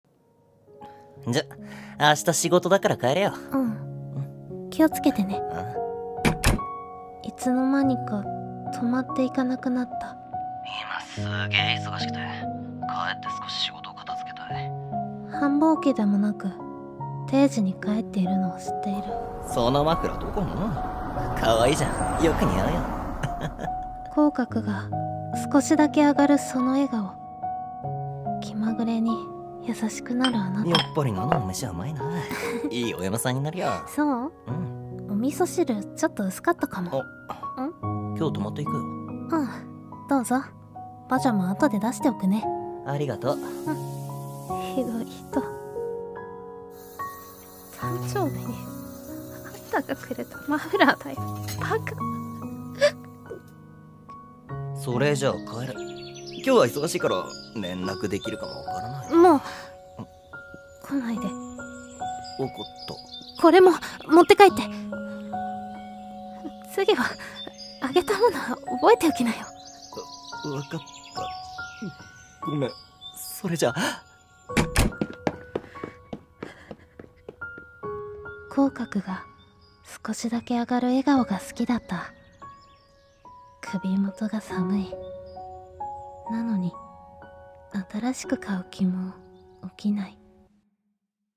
【2人声劇】寒凪のあなた